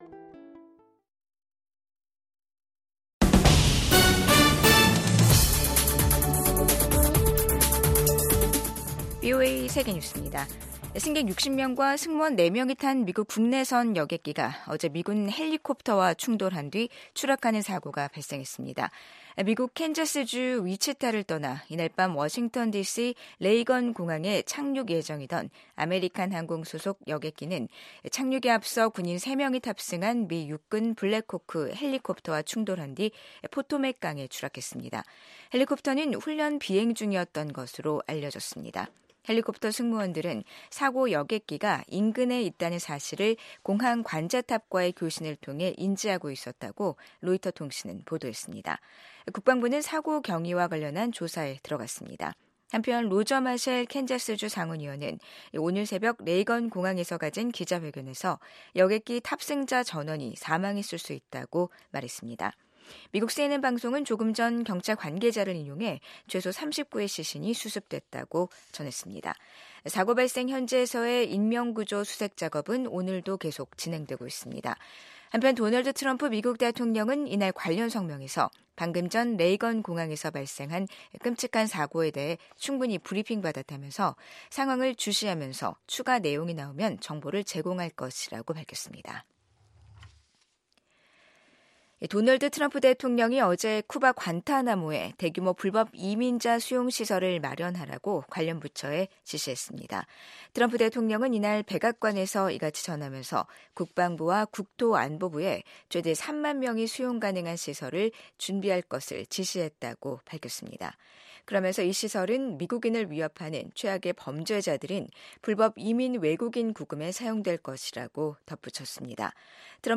VOA 한국어 간판 뉴스 프로그램 '뉴스 투데이', 2025년 1월 30일 2부 방송입니다. 미국 의회에서 한반도 문제를 담당할 소위원회 구성이 완료된 가운데 한반도 정책에는 변화가 없을 거란 전망이 나옵니다. 도널드 트럼프 미국 대통령이 동맹과의 미사일 방어를 강화하라고 지시한 데 대해 전문가들은 미한 통합미사일 방어 구축 의지를 확인한 것으로 해석했습니다.